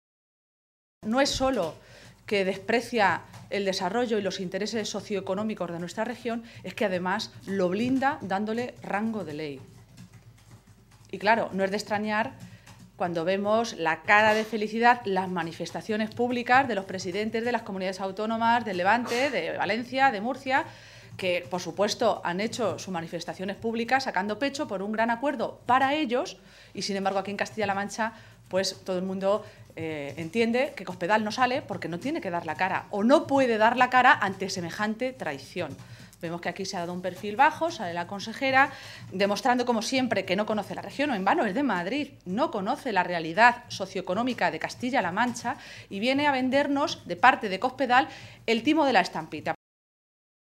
Maestre se ha pronunciado de esta manera esta mañana en Toledo, en una comparecencia ante los medios de comunicación, en la que ha valorado la información conocida ayer sobre la presentación, por parte del PP, de 8 enmiendas a la Ley de Evaluación ambiental, en las que se eleva a rango de Ley el memorándum firmado en su día por el Gobierno de España y los Gobiernos de Murcia y Valencia.
Cortes de audio de la rueda de prensa